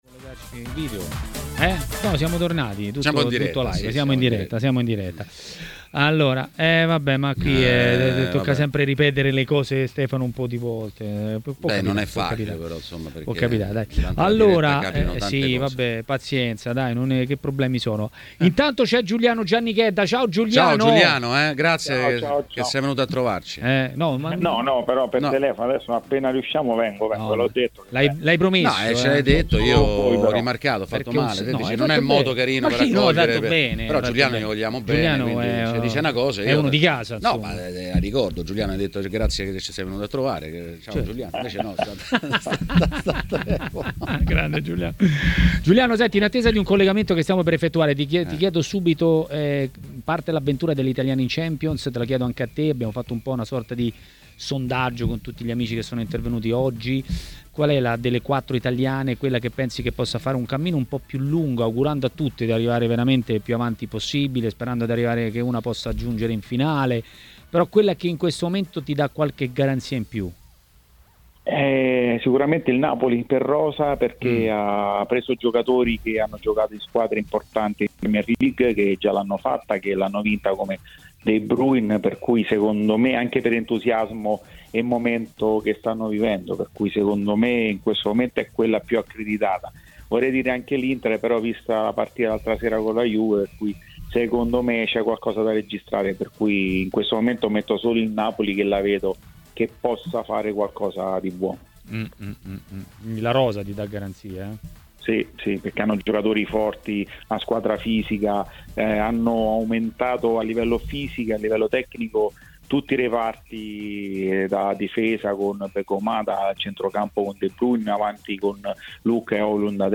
L'ex calciatore e tecnico Giuliano Giannichedda è stato ospite di Maracanà, trasmissione del pomeriggio di TMW Radio.